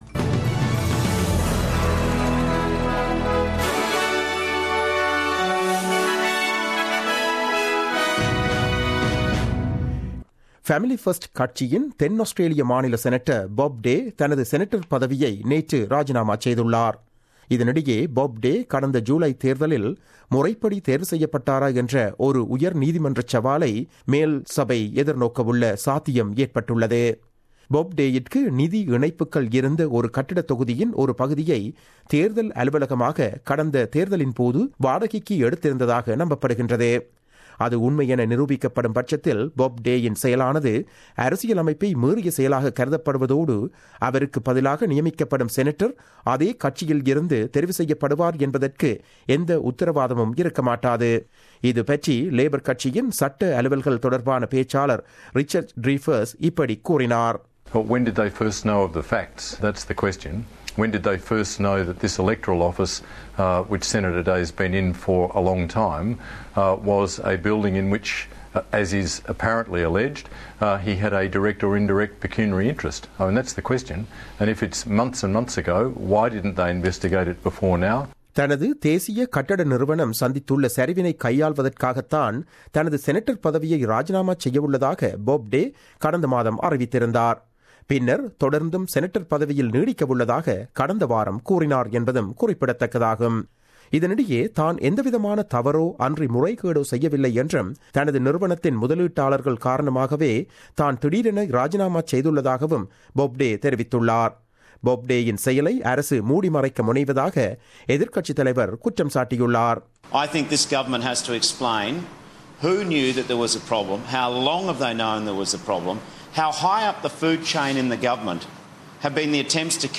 The news bulletin aired on 02 November 2016 at 8pm.